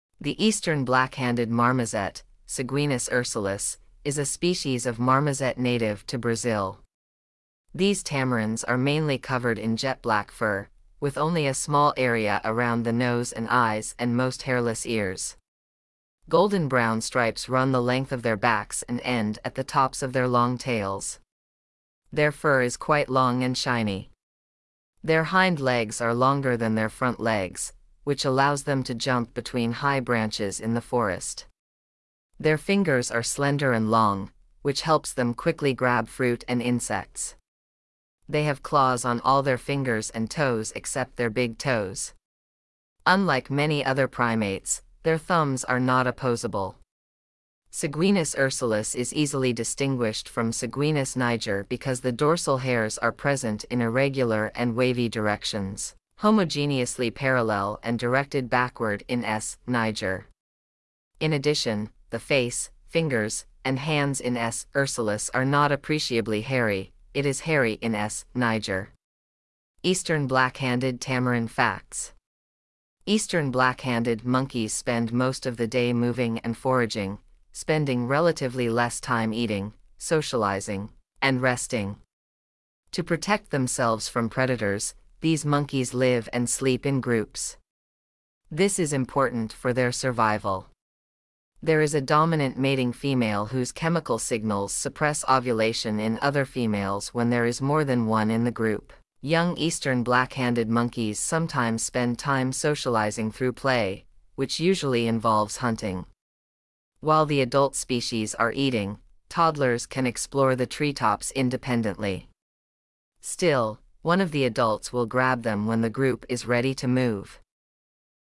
Eastern Black-handed Tamarin
Eastern-black-handed-marmoset.mp3